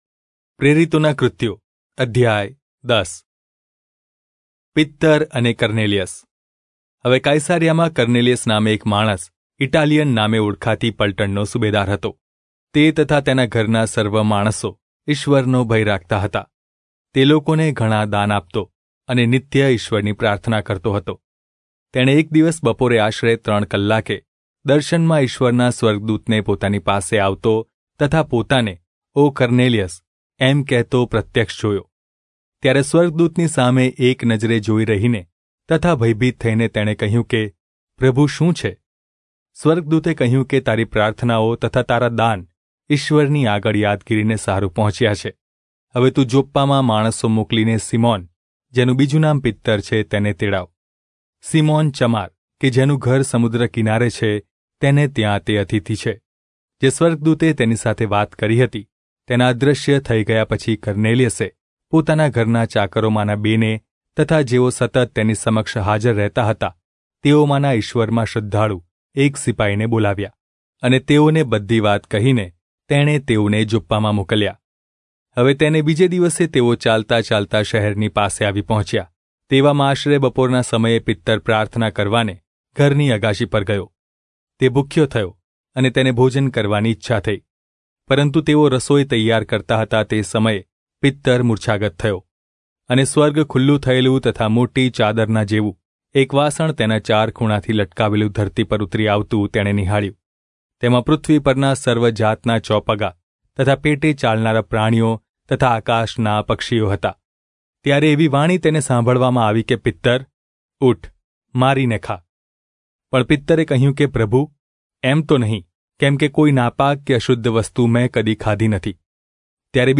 Gujarati Audio Bible - Acts 27 in Irvgu bible version